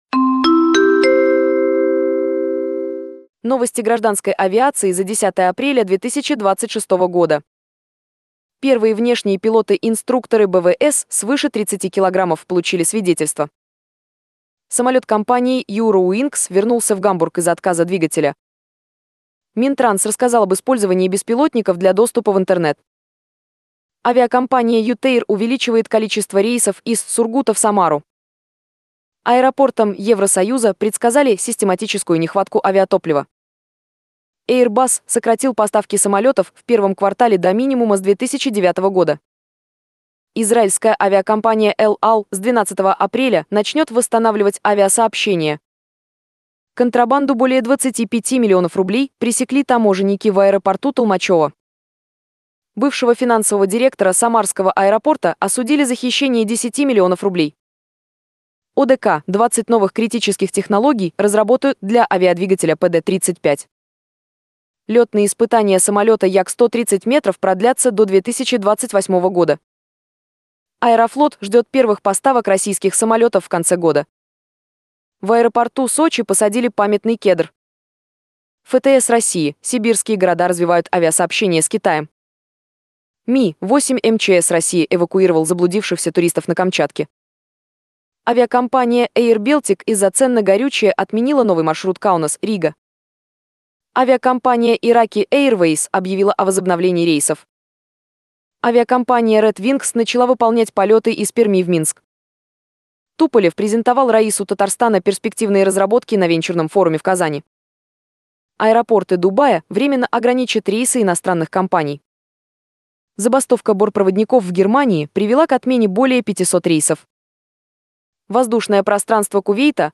Дайджест авиационных новостей 10 апреля 2026